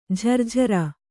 ♪ jharjhara